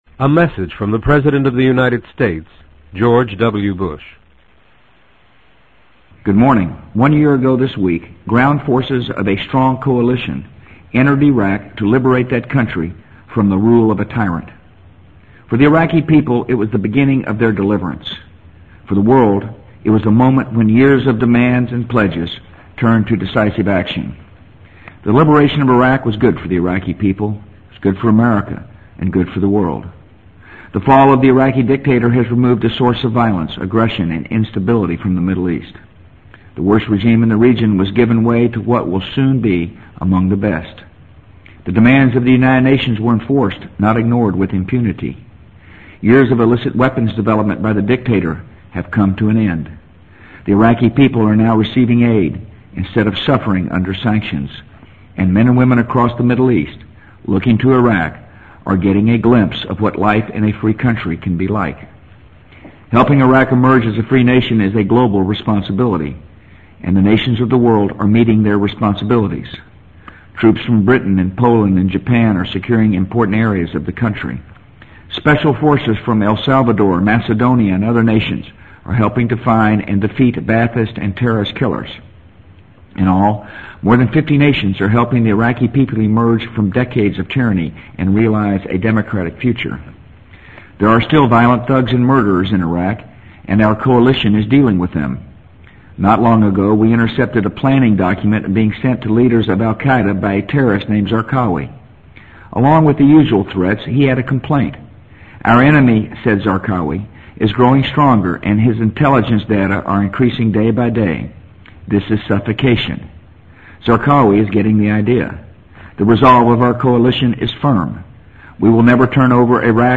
【美国总统George W. Bush电台演讲】2004-03-20 听力文件下载—在线英语听力室